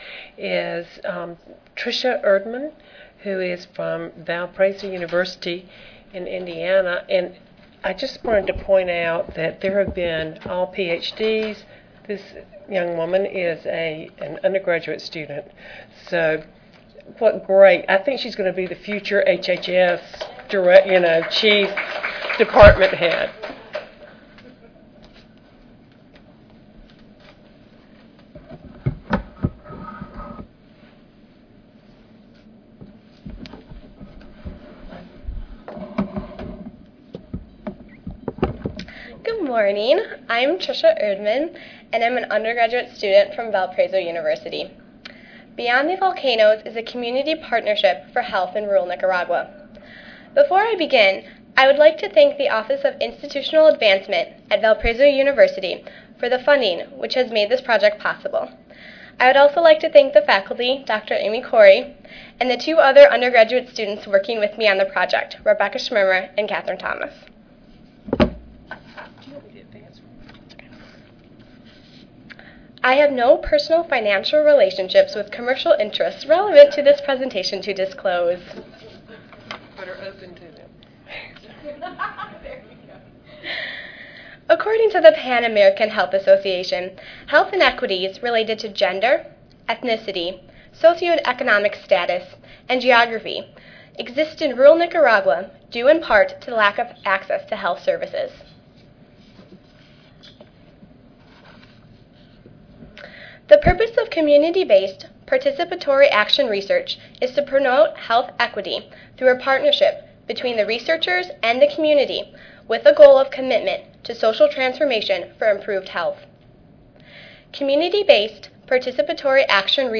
5135.0 Community Engagement Models Wednesday, October 29, 2008: 10:30 AM Oral Community engagement models are based on a collaboration with community entities.